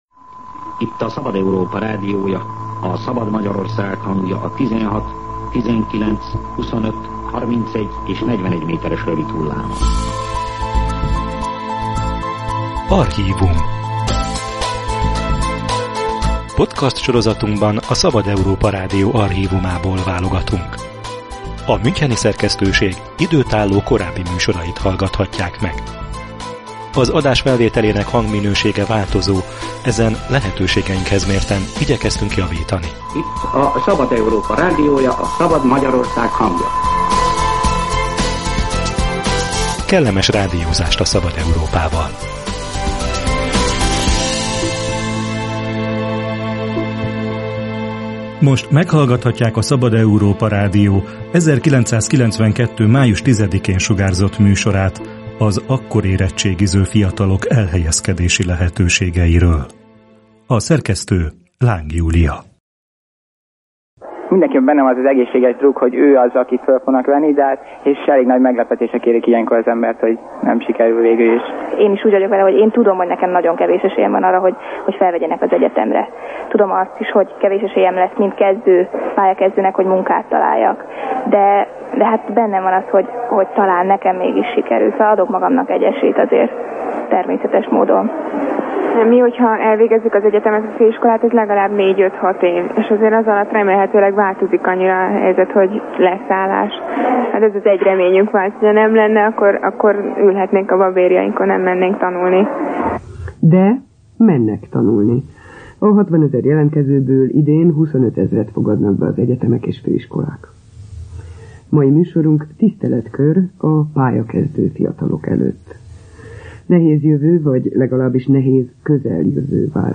Nem várnak támogatást a hivataloktól, felkészültek az önállóságra és keveseknek van esélye a továbbtanulásra - erről beszéltek a Szabad Európa Rádió 1992. május 10-én sugárzott műsorában az érettségiző fiatalok. Pillanatfelvétel az elhelyezkedés 30 évvel ezelőtti kihívásairól.